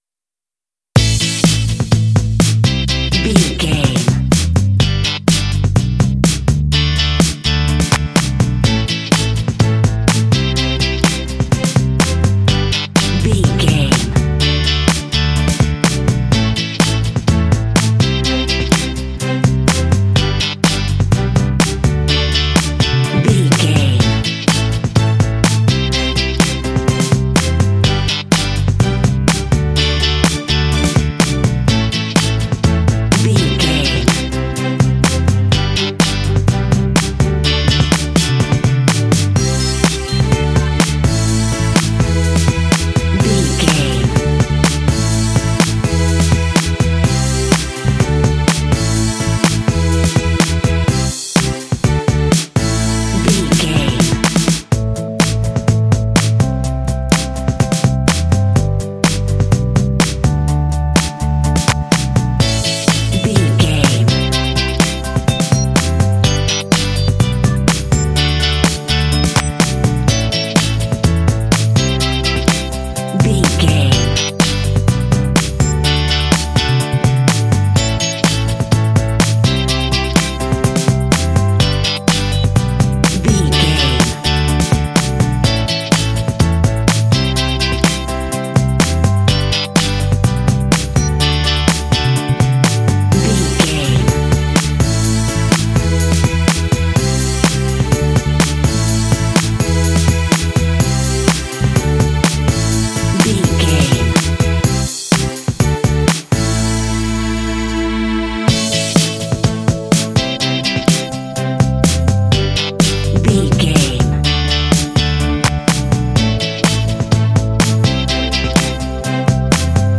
Aeolian/Minor
motivational
optimistic
happy
bright
strings
drums
bass guitar
electric guitar
pop
contemporary underscore
rock
indie